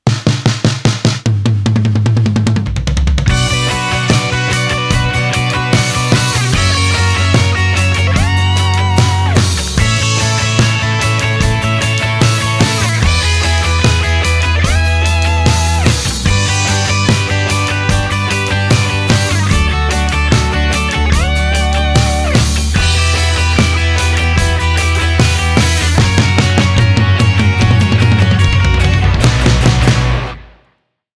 painstakingly re-creates the amazing soaring harmonies